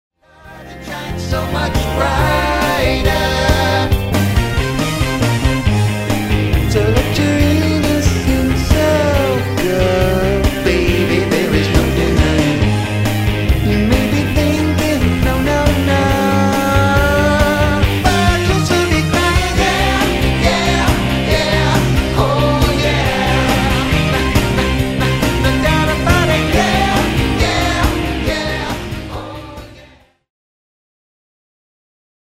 A WIDE VARIETY OF ROCK INCLUDING,